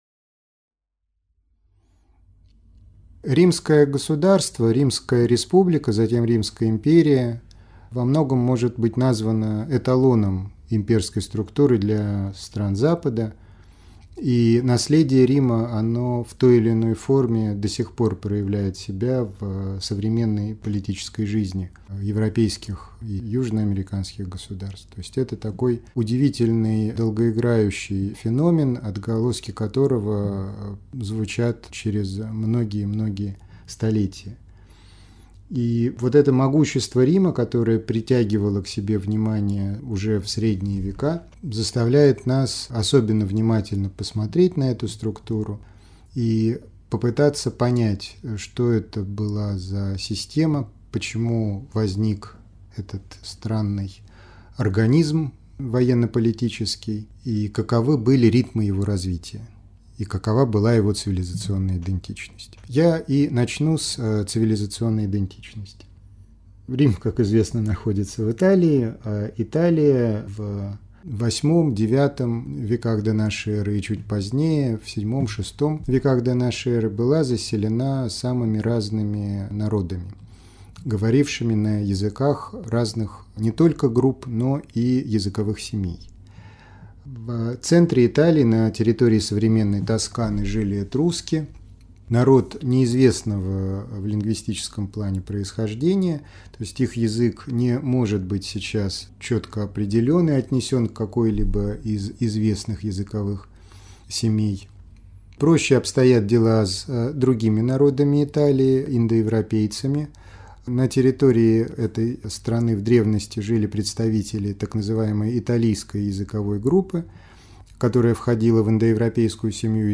Аудиокнига Рим. Республика как начало империи | Библиотека аудиокниг